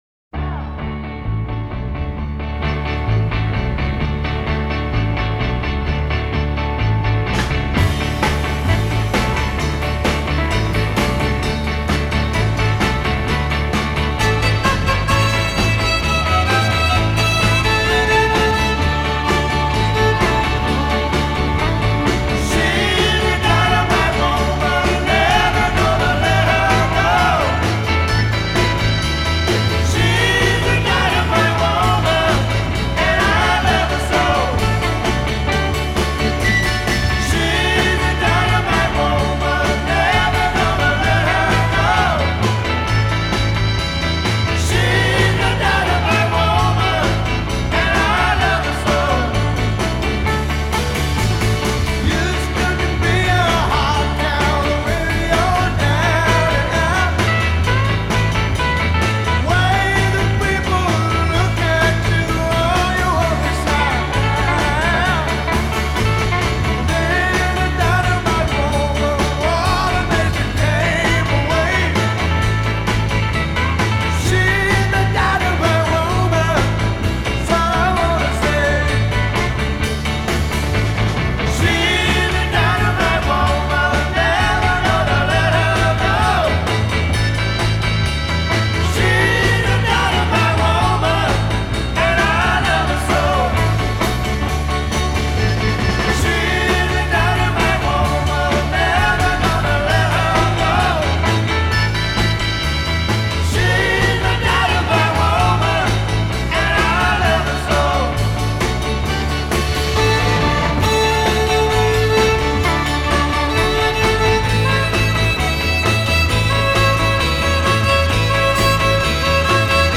1969 US Roots Rock